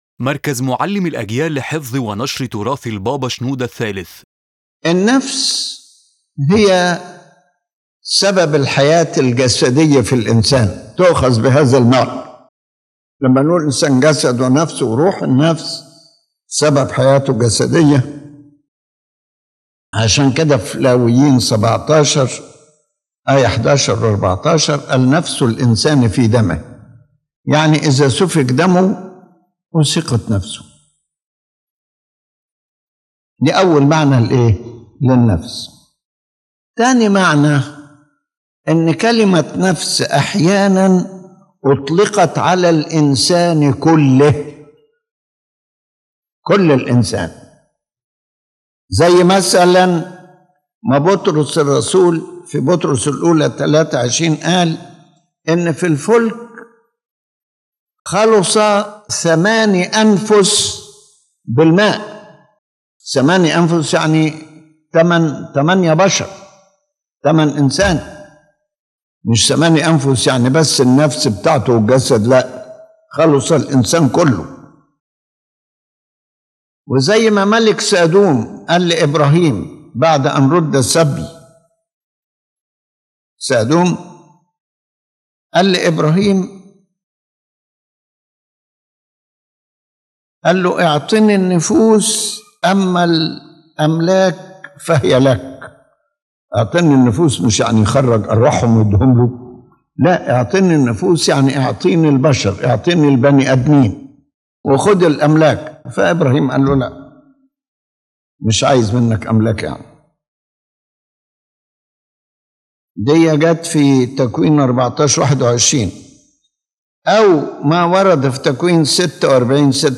His Holiness Pope Shenouda III explains the meaning of the word “soul” in the Holy Bible, showing the multiple uses and meanings of the term—whether referring to physical life, the entire human being, or the spirit. This explanation clarifies the doctrinal distinctions between soul, spirit, and body as presented in various biblical passages.